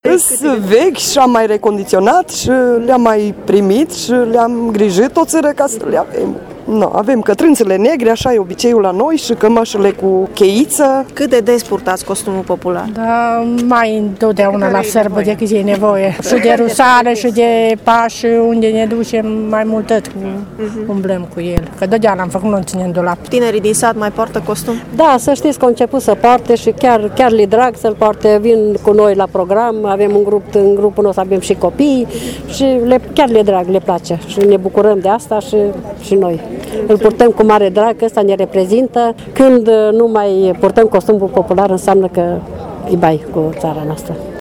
La paradă au venit și câteva zeci de mureșeni din toate colțurile județului, care au scos din lada de zestre costumele populare autentice primite de la bunici: